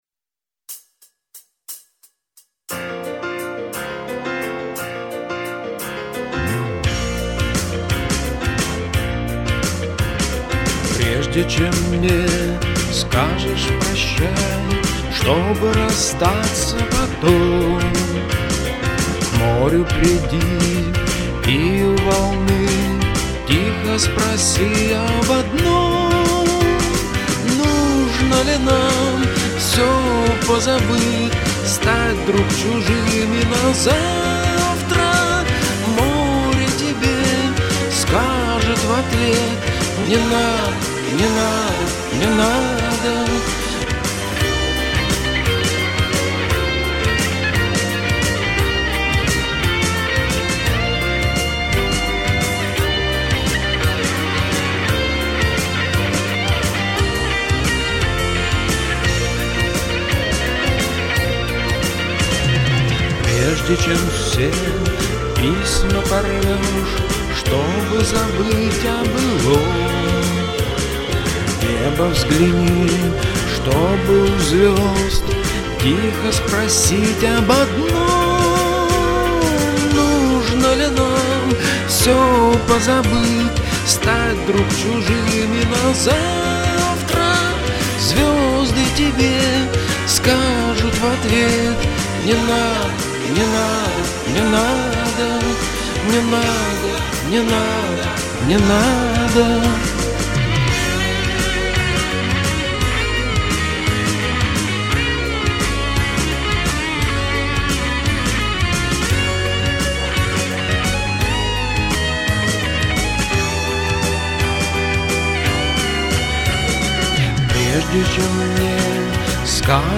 Поп (4932)